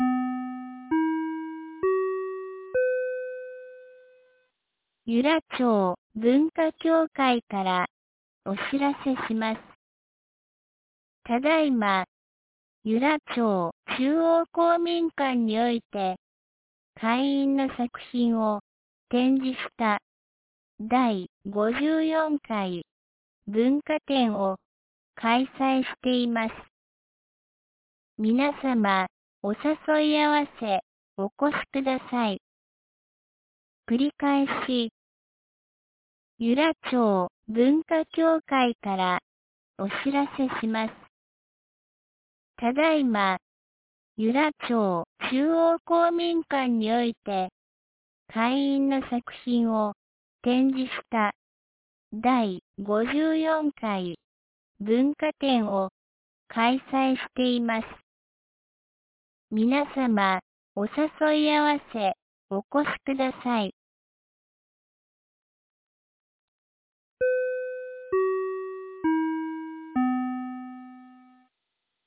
2019年11月03日 17時11分に、由良町から全地区へ放送がありました。